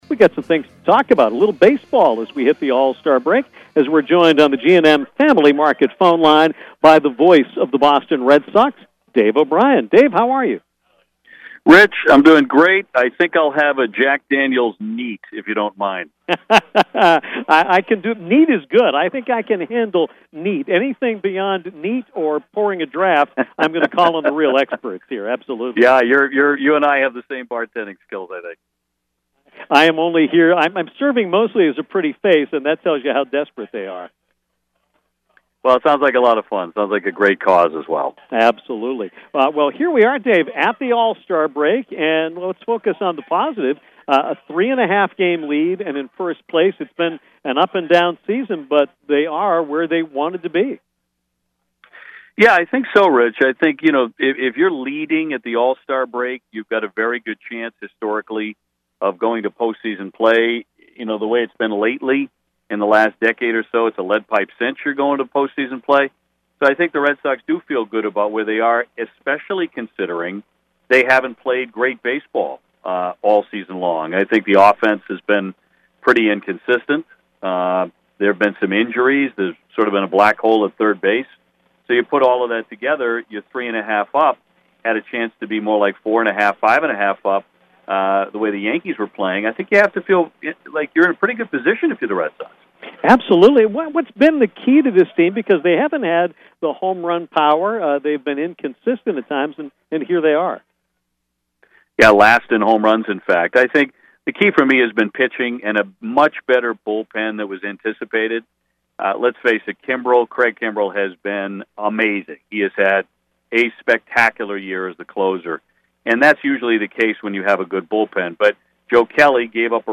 Red Sox broadcaster Dave O’Brien took some time during the All-Star break to chat with us about the Sox current season and look back on the 1967 “Impossible Dream” squad.